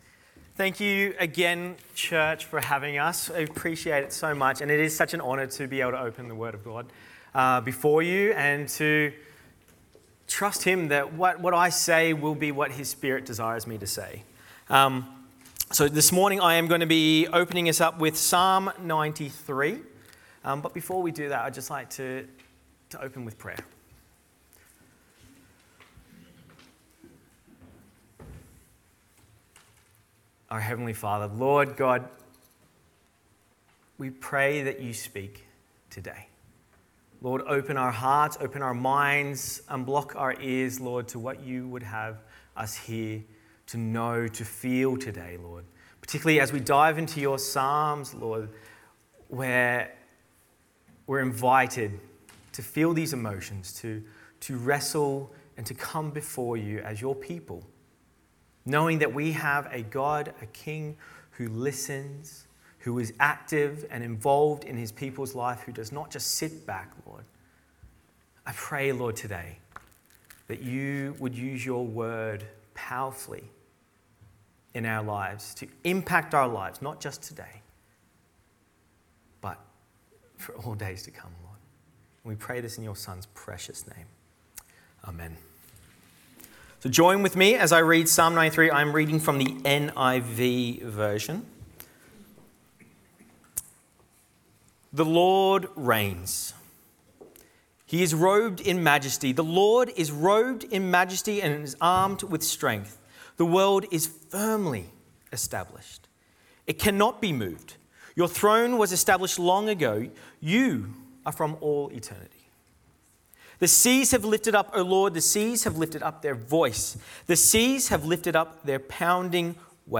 Sermons | Titirangi Baptist Church